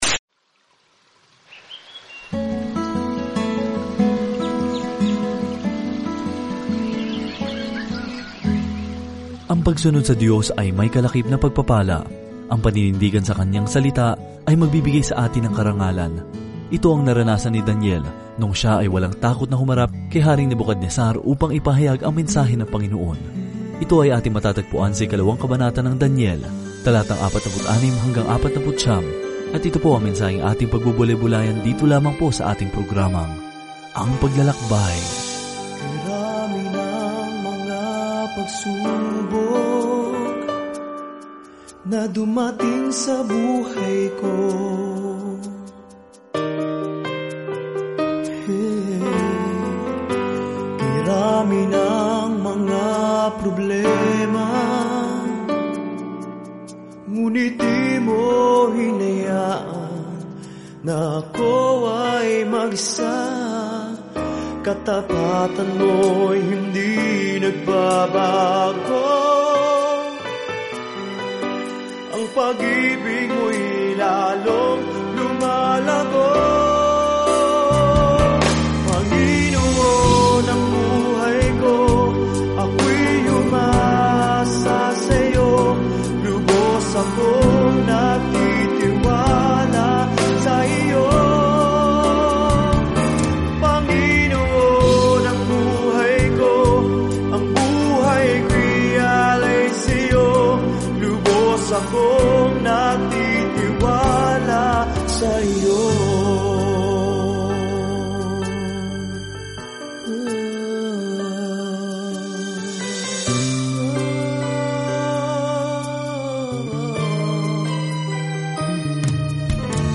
Banal na Kasulatan Daniel 2:44-49 Daniel 3:1-2 Araw 6 Umpisahan ang Gabay na Ito Araw 8 Tungkol sa Gabay na ito Ang aklat ni Daniel ay parehong talambuhay ng isang taong naniwala sa Diyos at isang makahulang pangitain kung sino ang mamamahala sa daigdig. Araw-araw na paglalakbay kay Daniel habang nakikinig ka sa audio study at nagbabasa ng mga piling talata mula sa salita ng Diyos.